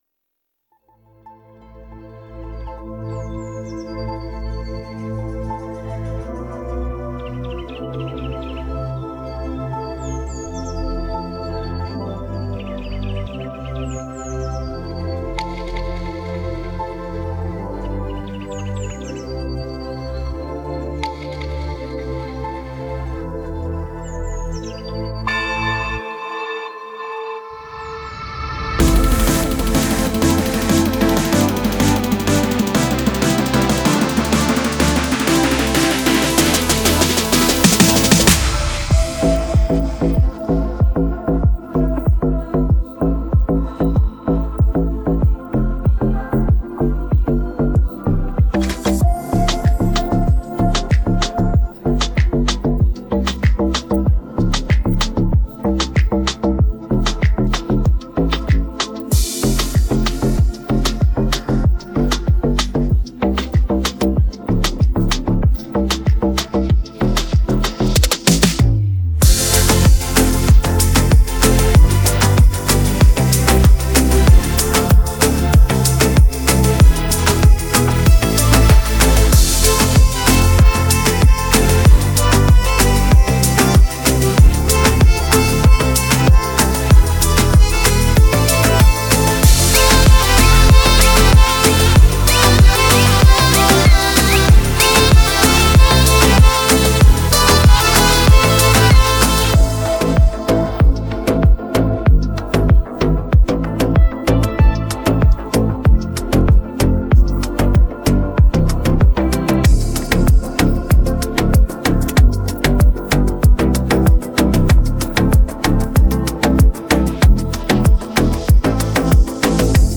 (минус)